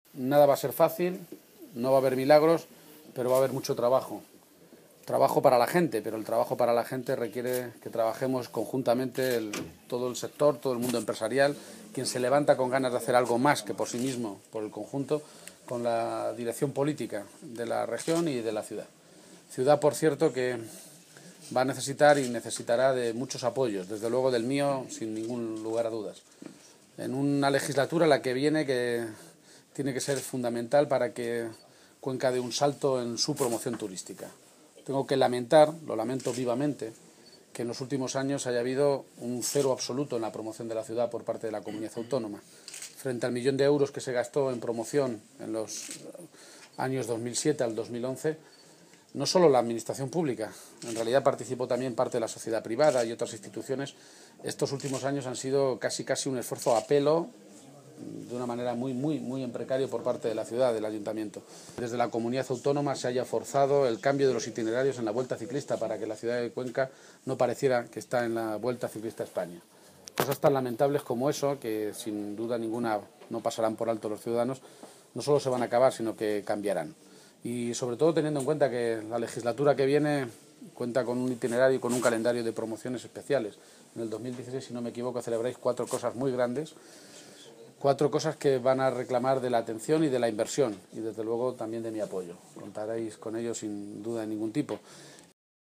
García-Page se pronunciaba der esta manera esta tarde, en la capital conquense, en una comparecencia ante los medios de comunicación previa a una reunión con la Cámara de Comercio provincial en la que se abordará, entre otros asuntos, la futura Ley regional de Cámaras de Comercio.